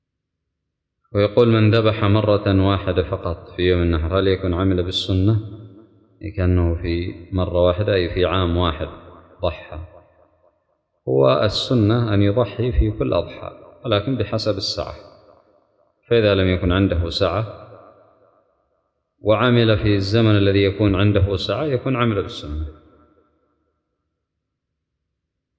:العنوان فتاوى عامة :التصنيف 1444-11-28 :تاريخ النشر 23 :عدد الزيارات البحث المؤلفات المقالات الفوائد الصوتيات الفتاوى الدروس الرئيسية من ذبح مرة في الأضحى هل يكون عمل بالسنة؟